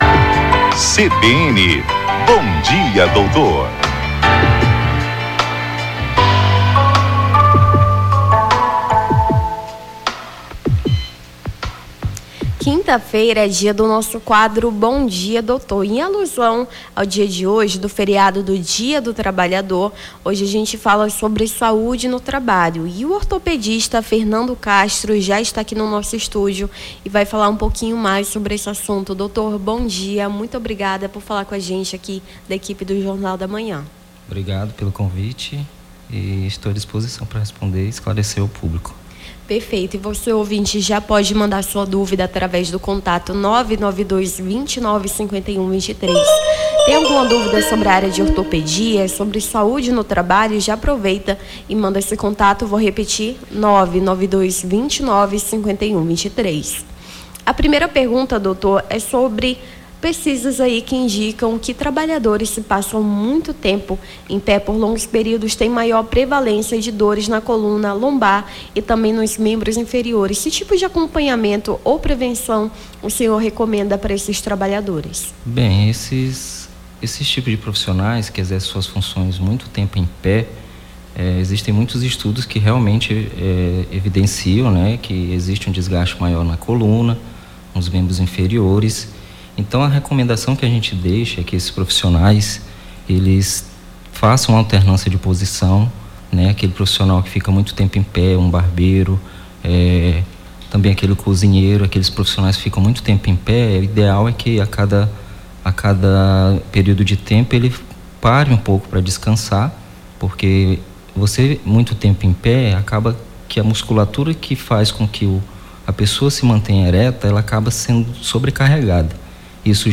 Bom Dia, Doutor: ortopedista fala sobre saúde no trabalho